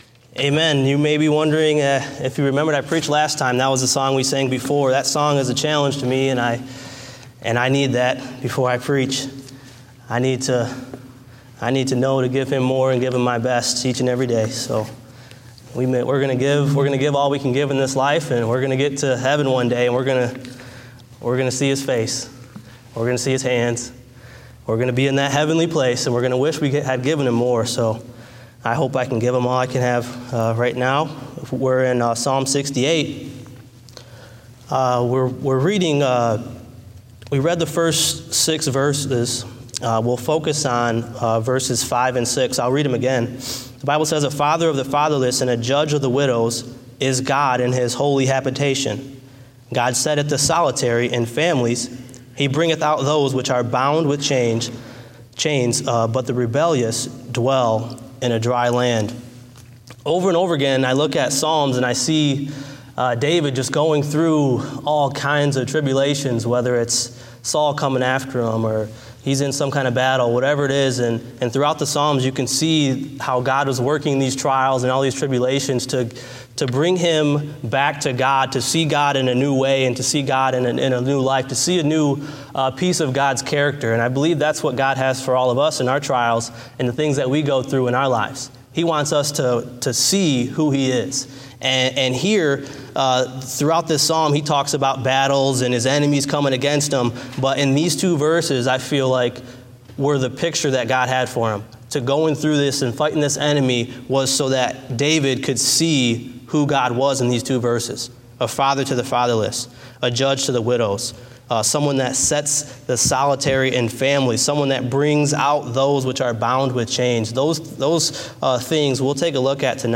Date: August 21, 2016 (Evening Service)